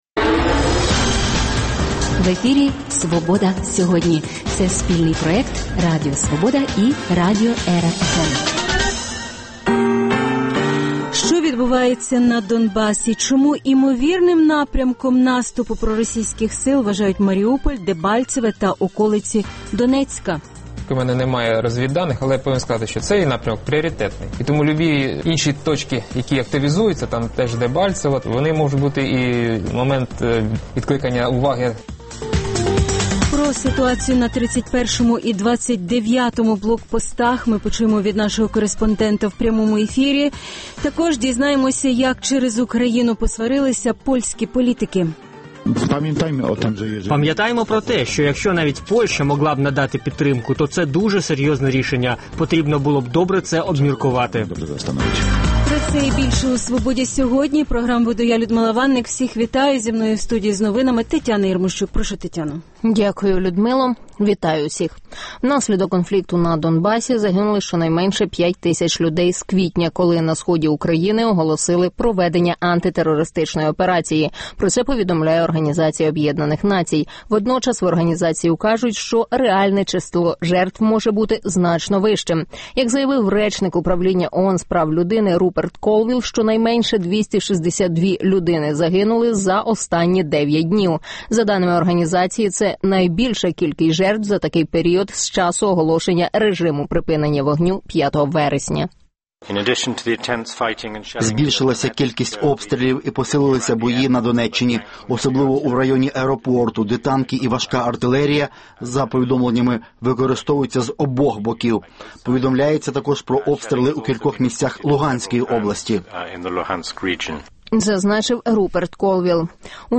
Що відбувається на Донбасі? Чому імовірним напрямком наступу проросійських сил вважають Маріуполь, Дебальцеве та околиці Донецька? Про ситуацію на 31 і 29 блокпостах почуємо від нашого кореспондента в прямому ефірі Як протидіяти панічним настроям у суспільстві?